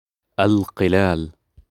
169. alcaller: Clay artisan or his helper. From Andalusi Arabic al qallal[ʔlqlaːl] (